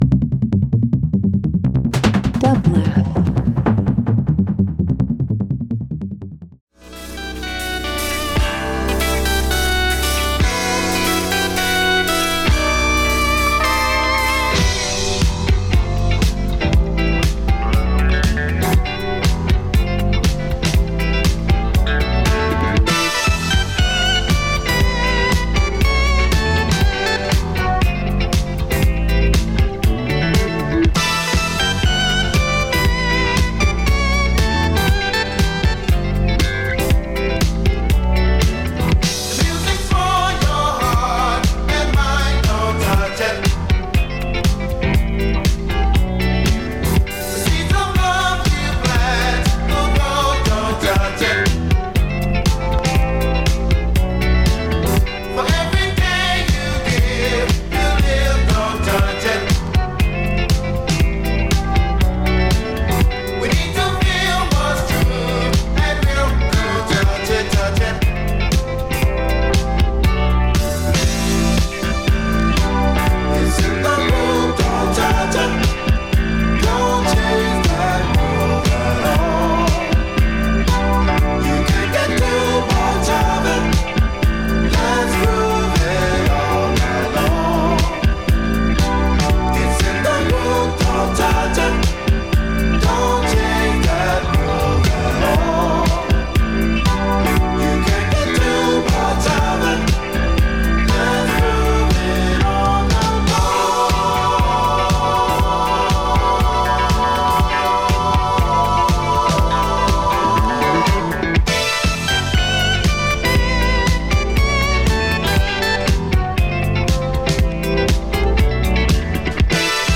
Funk/Soul Hip Hop R&B